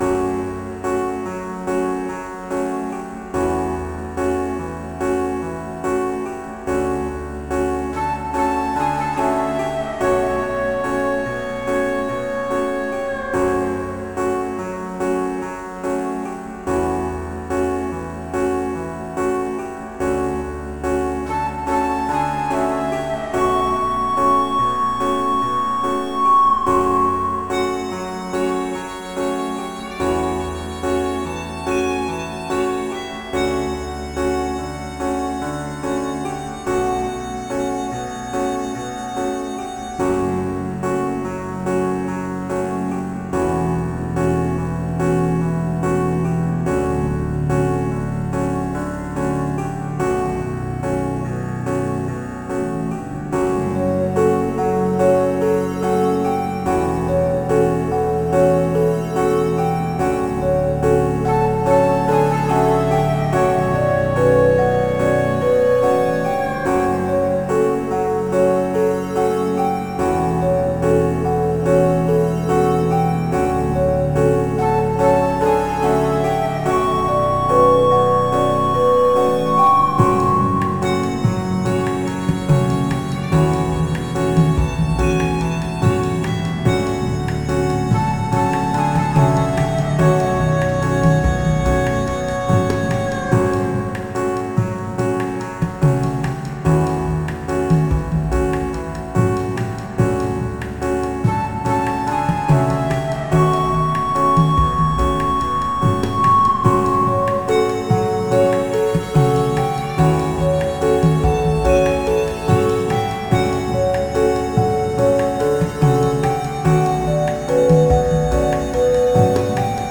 "A" -- Piano. 4 bars, no tail.
"B" -- Flute. 8 bars + 1 bar tail.
"C" -- Pipes. 4 bars + 1 bar tail.
"D" -- Drone. 8 bars + 1 bar tail.
"E" -- Percussion. 8 bars + 1 bar tail.
"F" -- Kalimba. 8 bars + 1 bar tail.